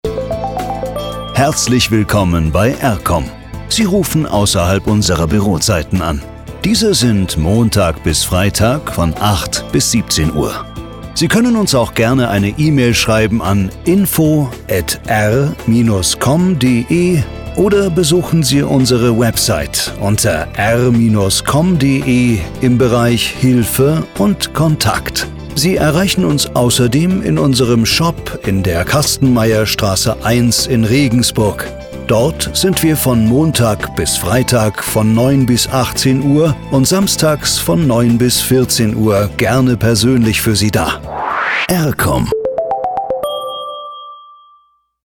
Anrufbeantworter außerhalb der Bürozeiten
R-KOM-AB-ausserhalb-Buerozeiten-8-17Uhr.mp3